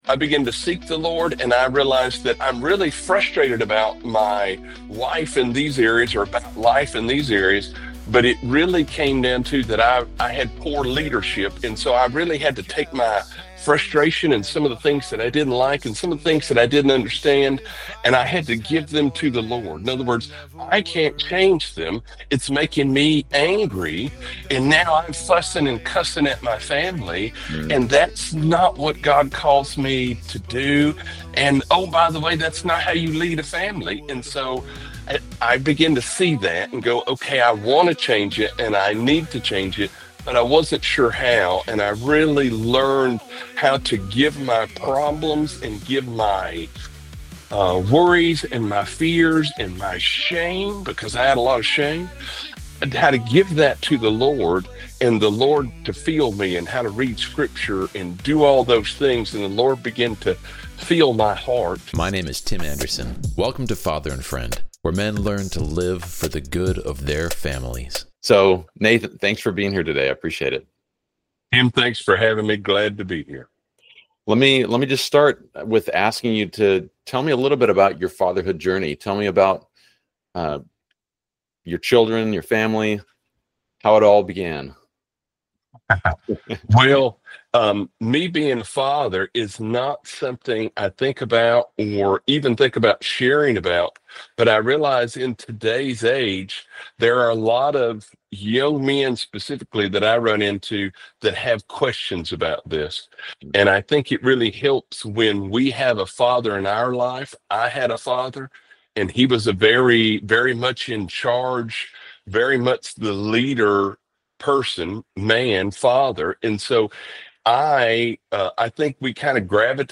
Father & Friend: Interviews With Everyday Dads And Fatherhood Experts – Podcast